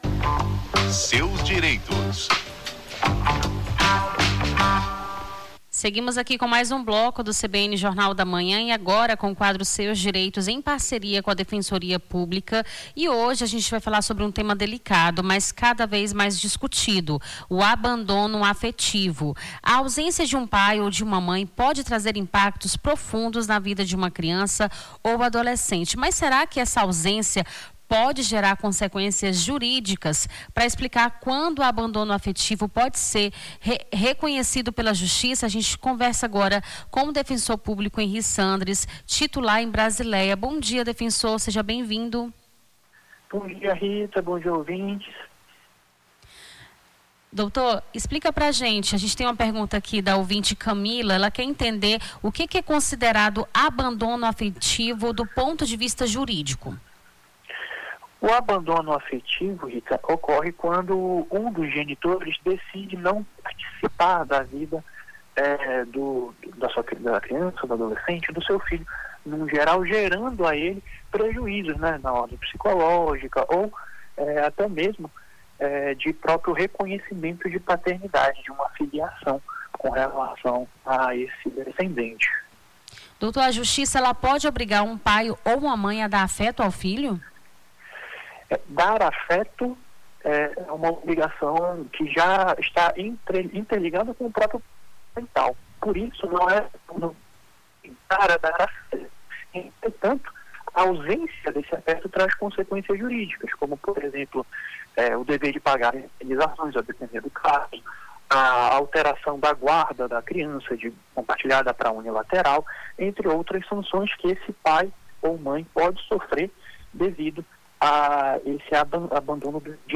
Seus Direitos: advogado esclarece dúvidas sobre direito de família